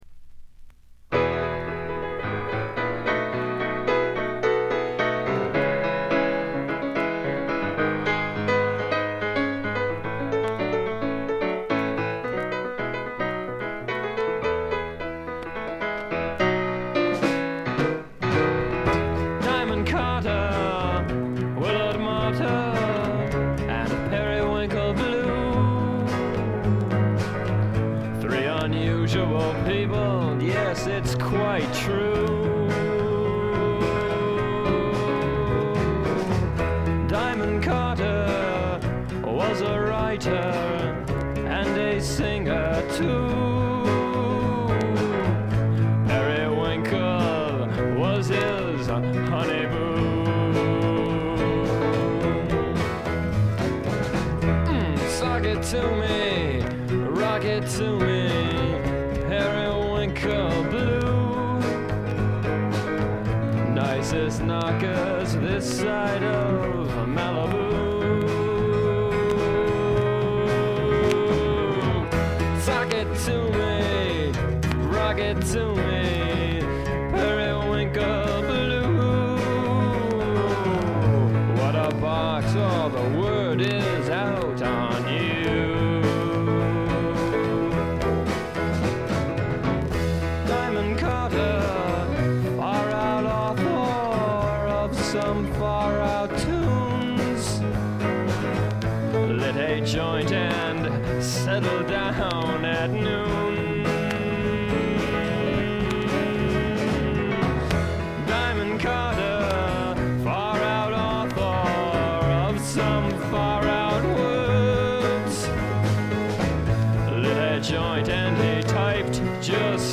プレス起因でしょうがA1, B1でチリプチ多め大きめに出ます。
試聴曲は現品からの取り込み音源です。